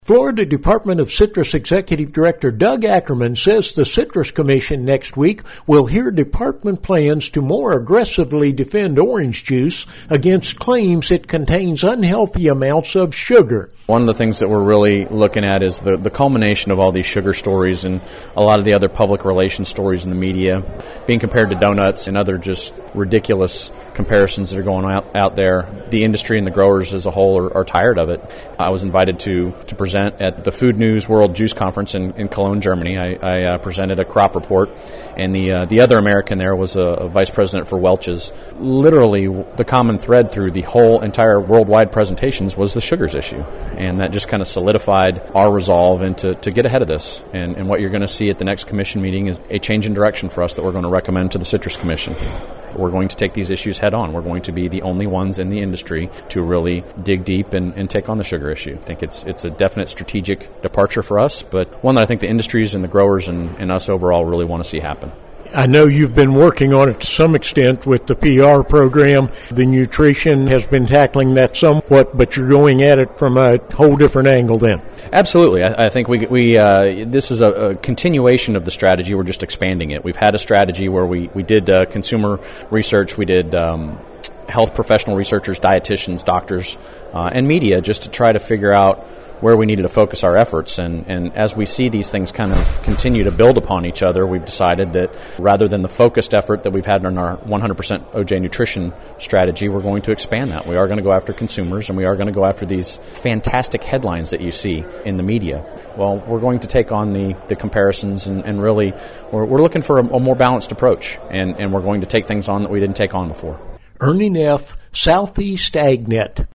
The Florida Citrus Commission next week will hear Florida Department of Citrus plans to more aggressively defend orange juice against claims that OJ contains unhealthy amounts of sugar. Department Executive Director Doug Ackerman reports.